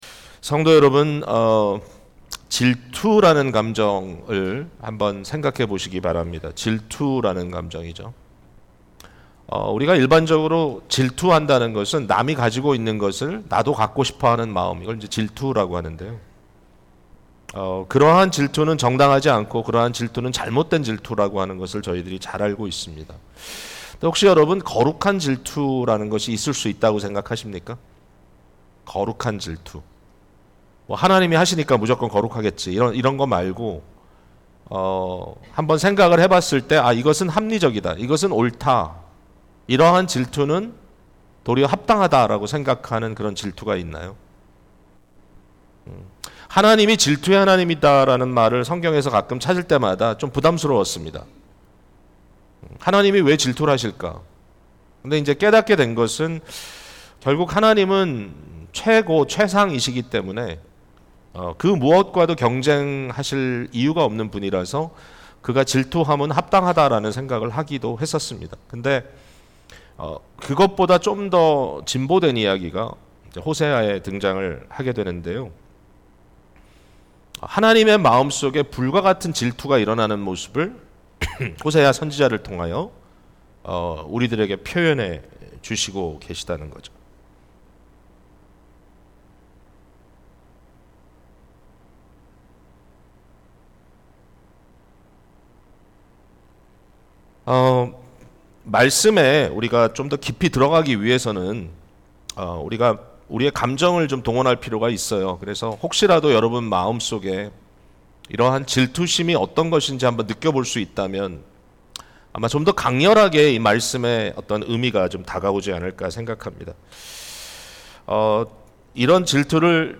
사랑방 말씀 나눔을 위한 질문 Sermon Discussion Questions 1.